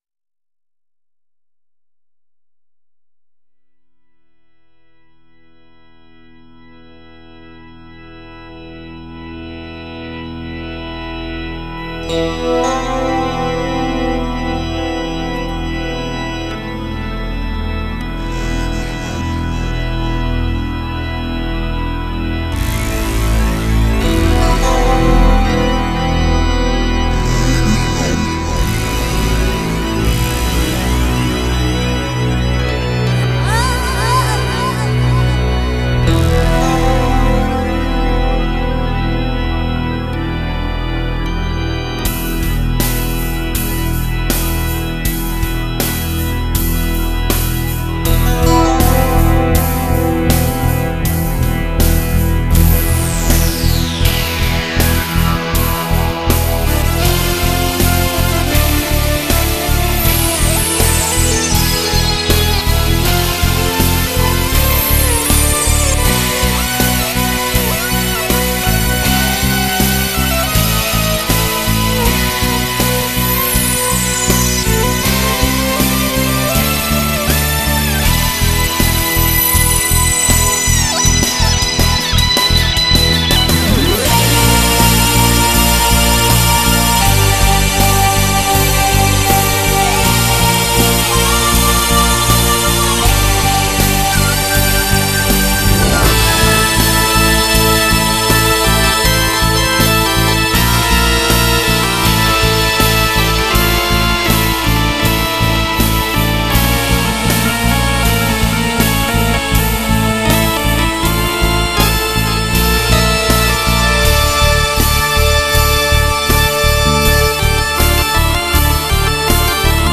Arabic-02.mp3